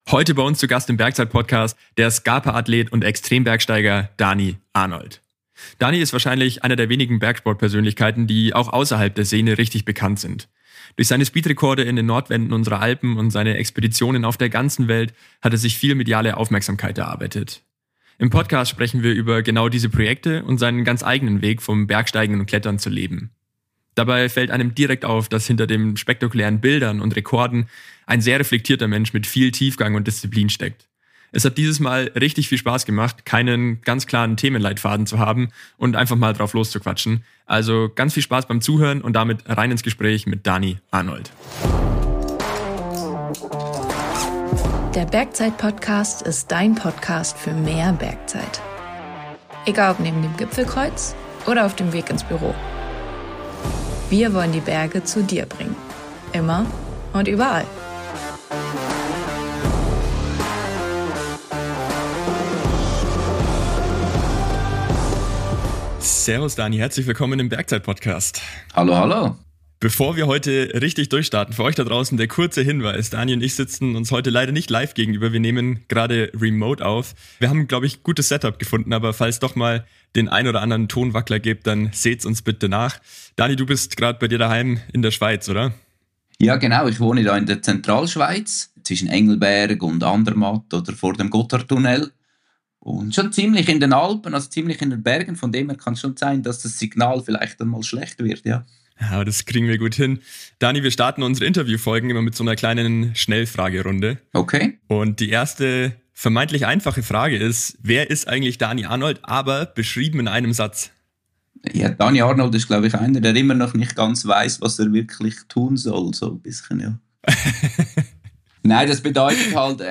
#91 Interview mit Dani Arnold